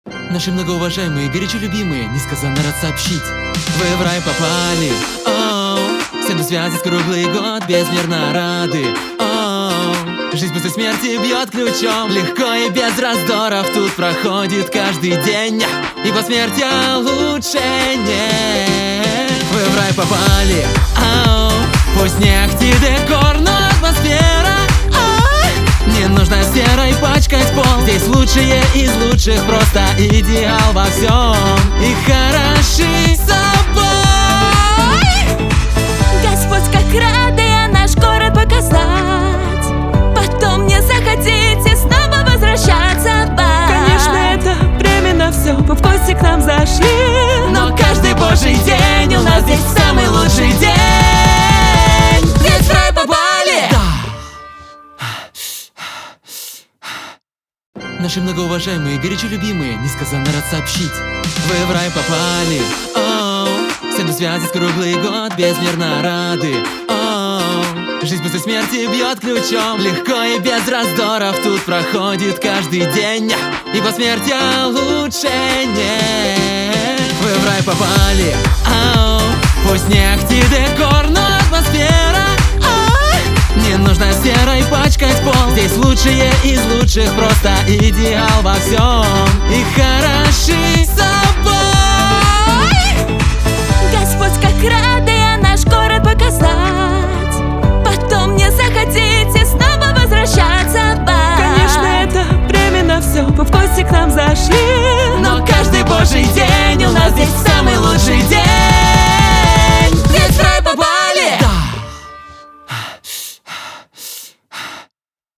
RUS cover